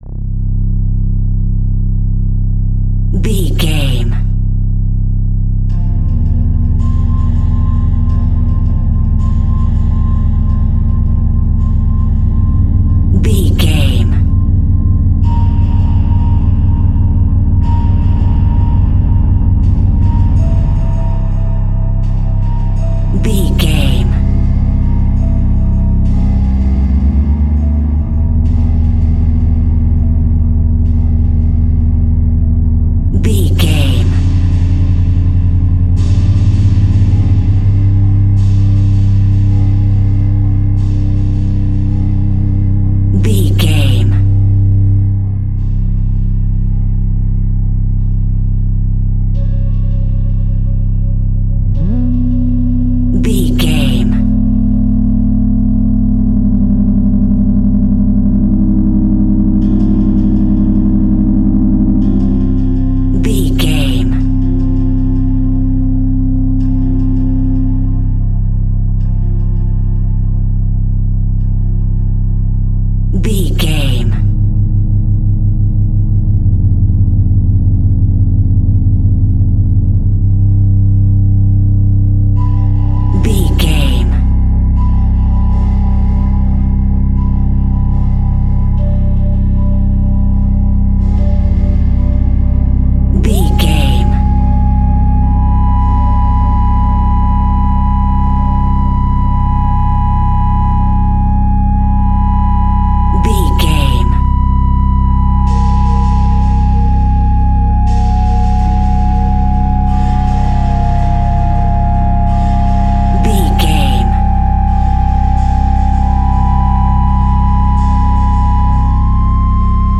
Retro Scary Movie Music.
Aeolian/Minor
Slow
tension
ominous
dark
eerie
synthesiser
organ